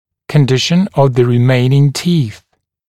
[kən’dɪʃn əv ðə rɪ’meɪnɪŋ tiːθ][кэн’дишн ов зэ ри’мэйнин ти:с]состояние оставшихся зубов